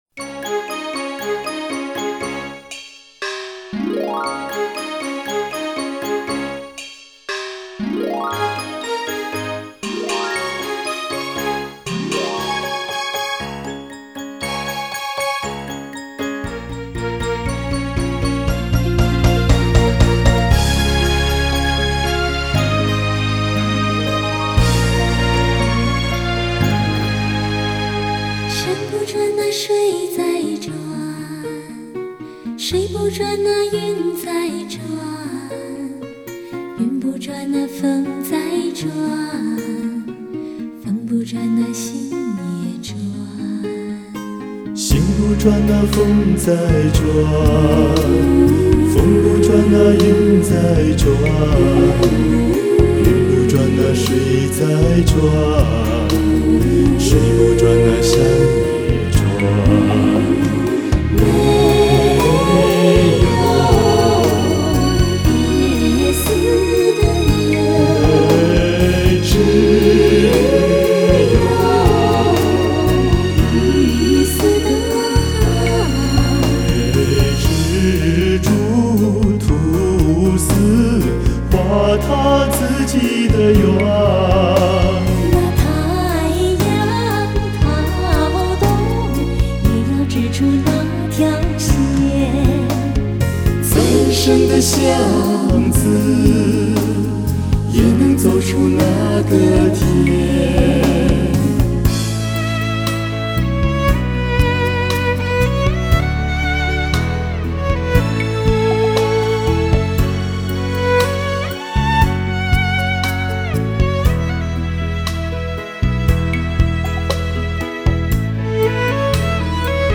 甜美悠揚的嗓音和極為專業的和音水準。
一組用至純至美的溫馨浸透您心靈的男女聲閤唱集。
錄音機：TASCAMA-80 24軌糢擬錄音機
MIC:U-87 非常廣泛使用的一種電容麥尅風